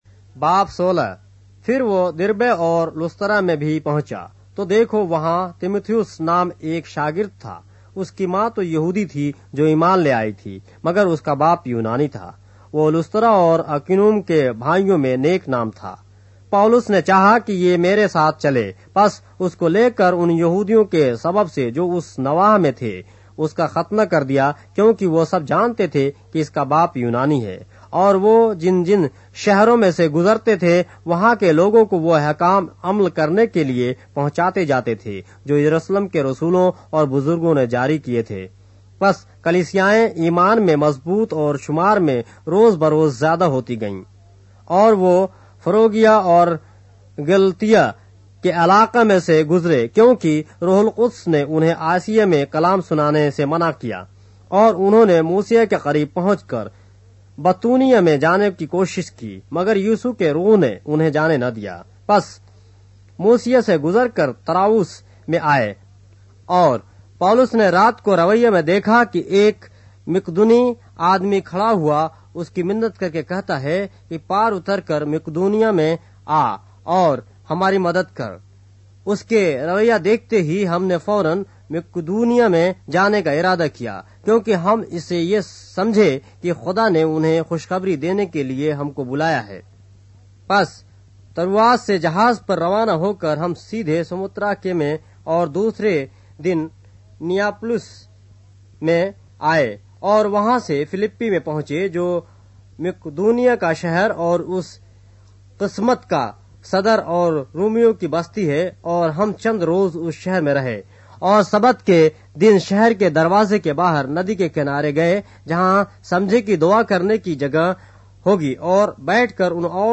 اردو بائبل کے باب - آڈیو روایت کے ساتھ - Acts, chapter 16 of the Holy Bible in Urdu